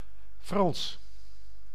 Ääntäminen
Ääntäminen : IPA: /frɑns/ Lyhenteet ja supistumat Fr. Haettu sana löytyi näillä lähdekielillä: hollanti Käännös Ääninäyte Adjektiivit 1.